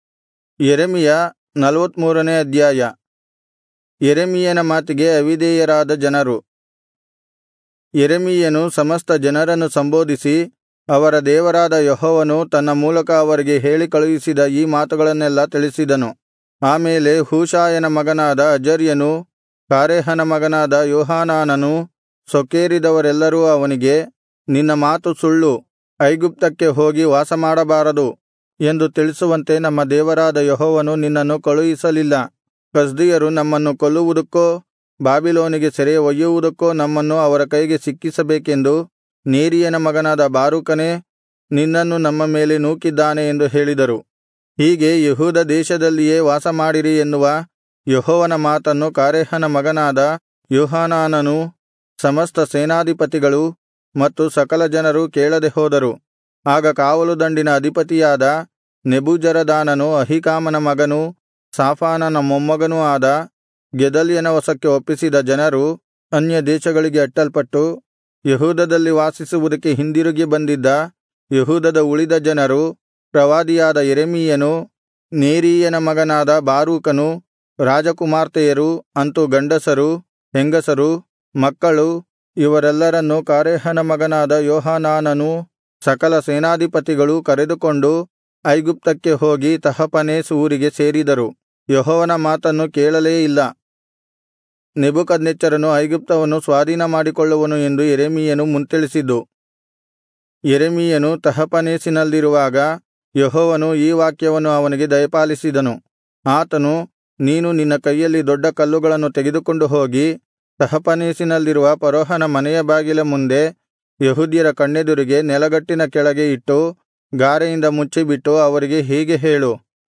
Kannada Audio Bible - Jeremiah 20 in Irvkn bible version